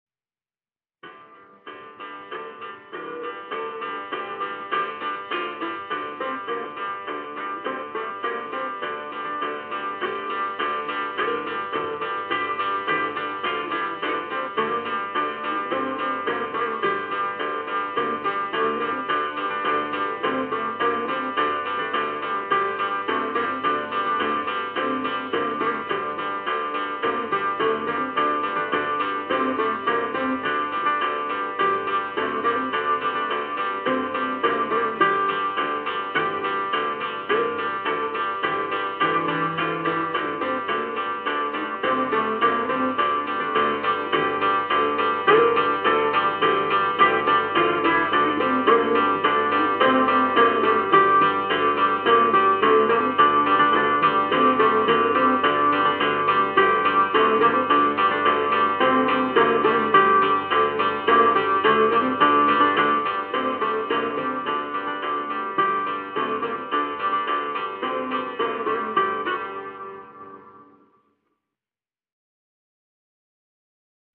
Marcha